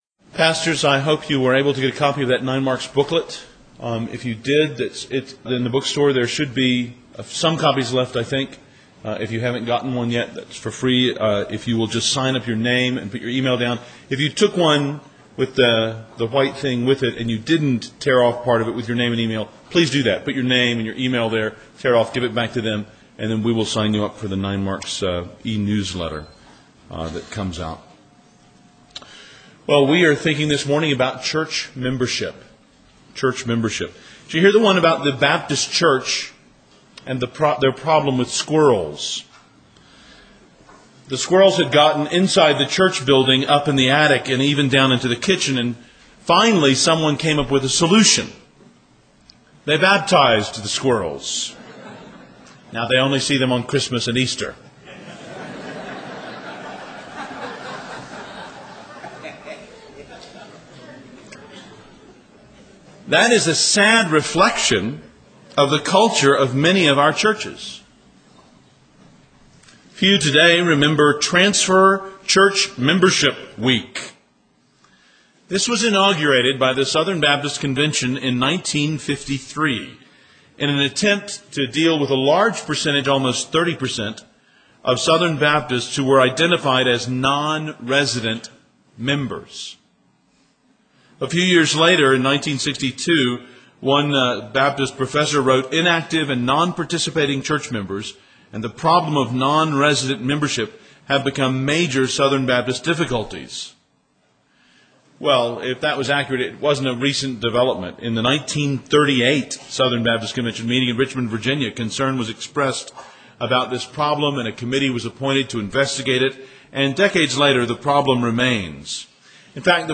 08 Q & A Session